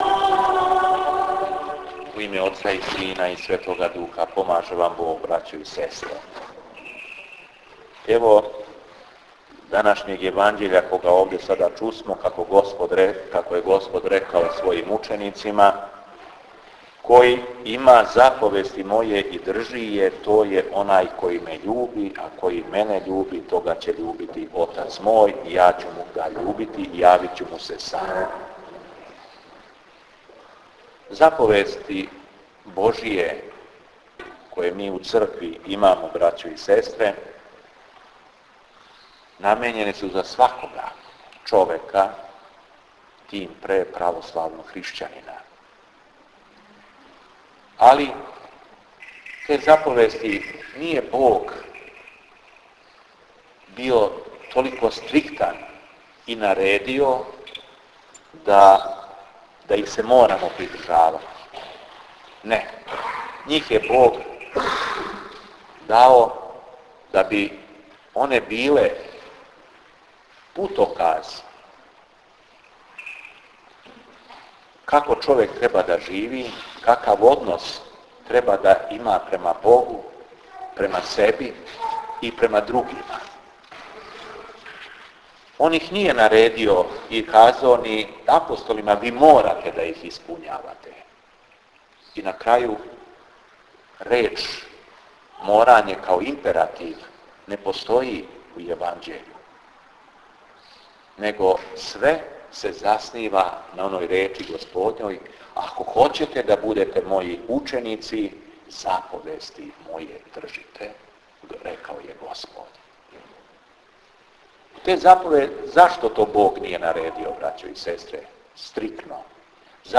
Беседа у манастиру Дивостин код Крагујевца